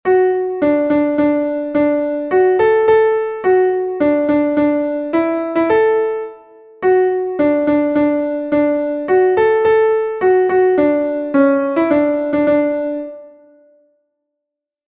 Entoación a capella
Melodía 2/4 en Sol M